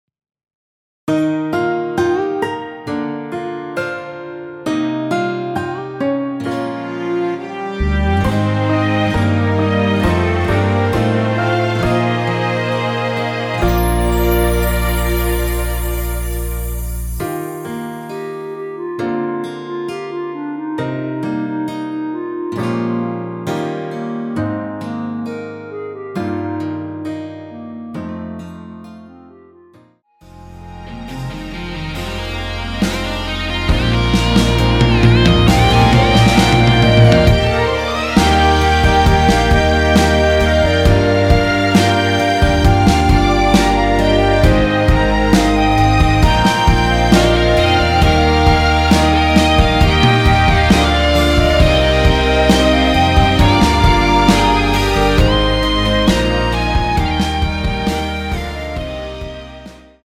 원키멜로디 포함된 MR입니다.
Eb
앞부분30초, 뒷부분30초씩 편집해서 올려 드리고 있습니다.
중간에 음이 끈어지고 다시 나오는 이유는